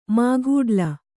♪ maghūḍla